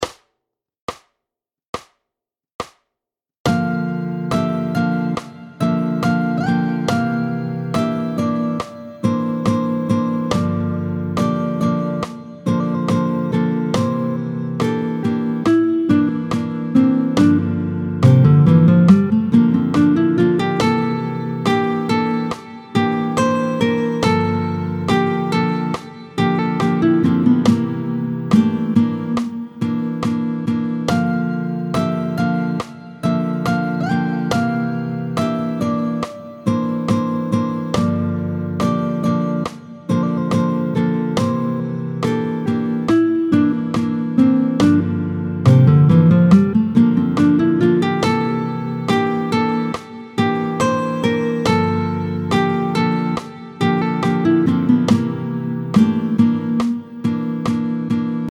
29-05 Le IIm7 / V9 en Fa, tempo 70